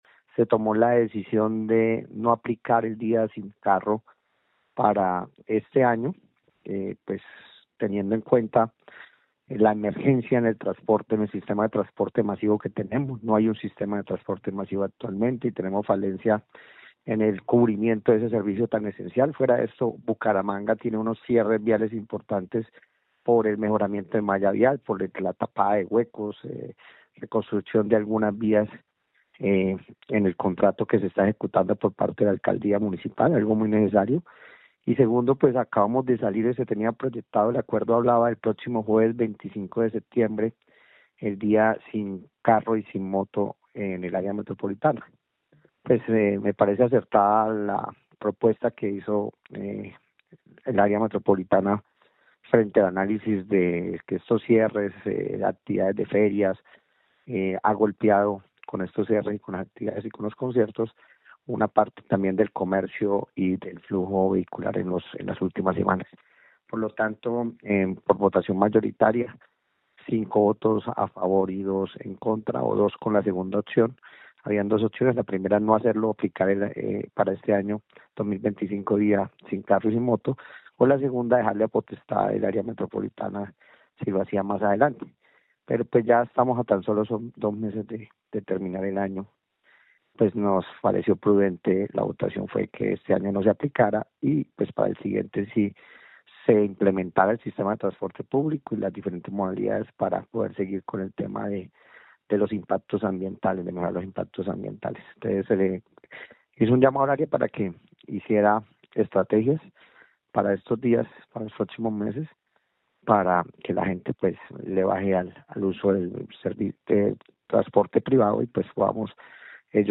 Cristian Reyes, concejal de Bucaramanga